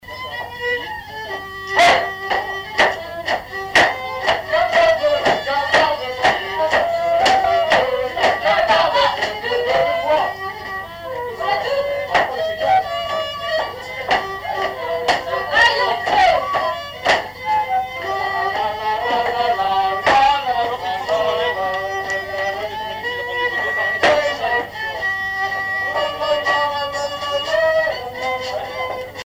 Chants brefs - A danser
Répertoire d'un bal folk par de jeunes musiciens locaux
Pièce musicale inédite